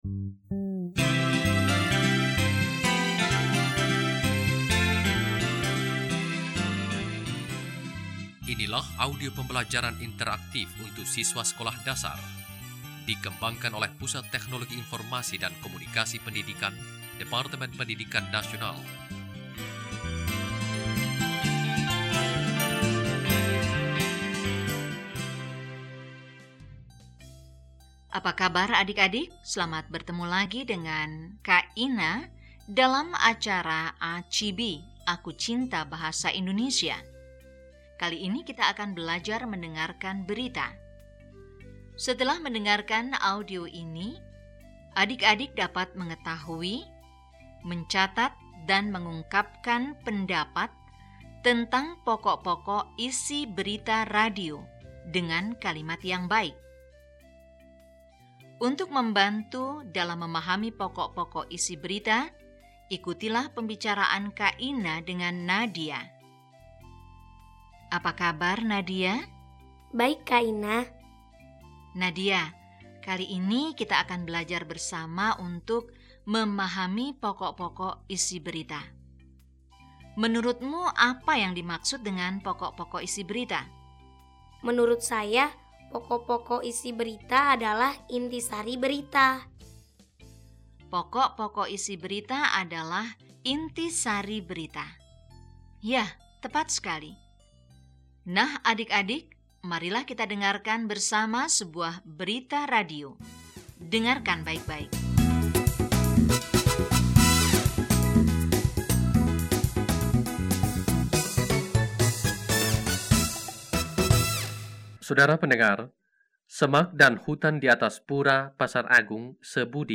Mendengarkan Berita Radio tentang Kebakaran Hutan (AUDIO) - Bahasa Indonesia - Kelas VI.mp3